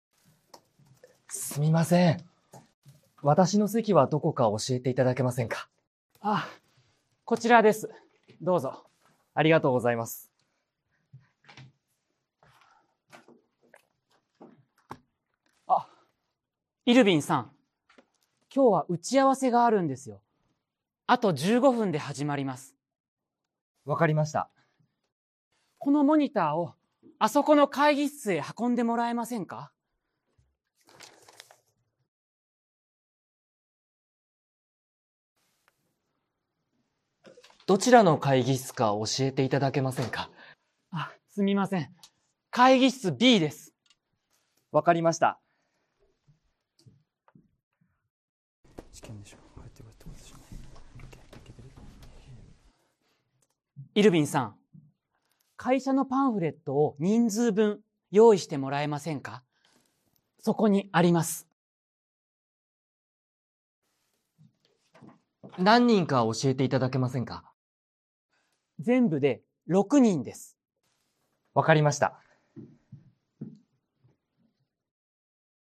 Role-play Setup
Scene: A new employee’s first day at the office. They need to ask questions about their assigned seat, an upcoming meeting, and contact information, all while using appropriate polite language.